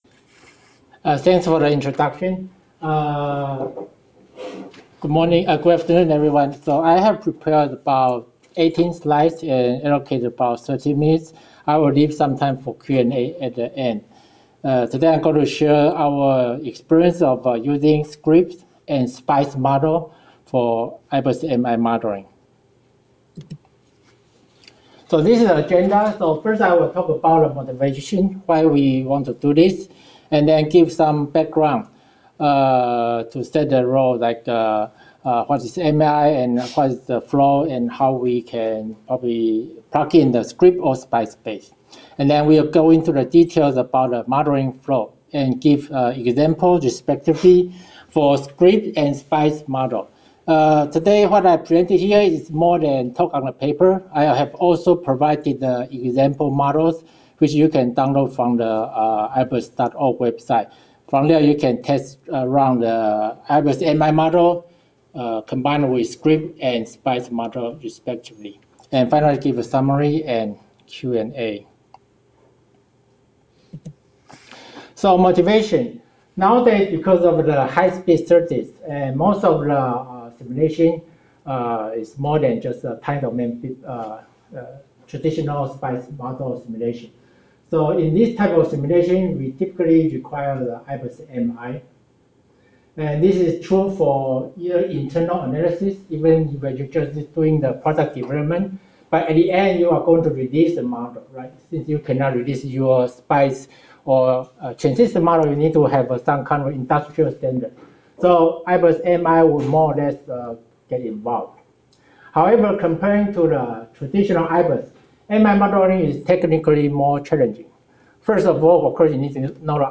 Presented at the EPEPS IBIS Summit at San Jose [MP3 Audio] Chinese, 45 min.